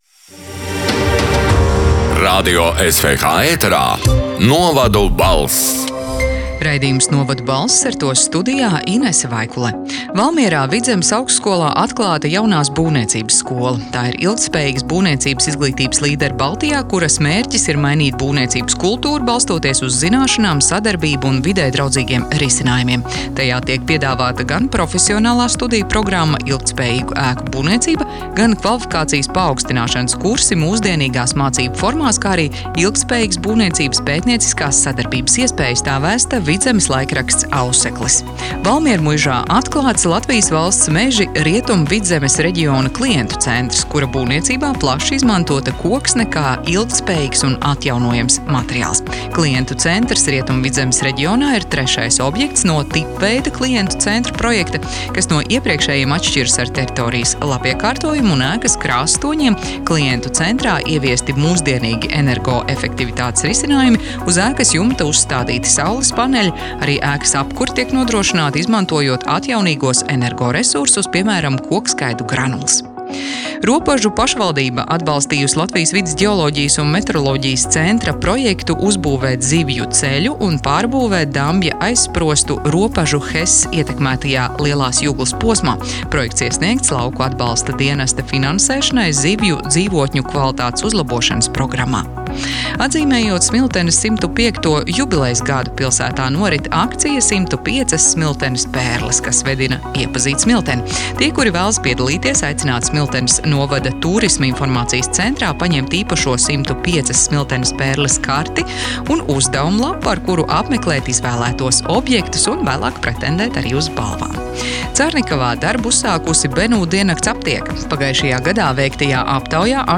Radio SWH ēterā divreiz nedēļā izskan ziņu raidījums “Novadu balss”, kurā iekļautas Latvijas reģionālo mediju sagatavotās ziņas. Raidījumā Radio SWH ziņu dienests apkopo aktuālāko no laikrakstiem “Auseklis”, “Kurzemes Vārds”, “Zemgales Ziņas” un ”Vietējā Latgales Avīze”.
“Novadu balss” 12. februāra ziņu raidījuma ieraksts: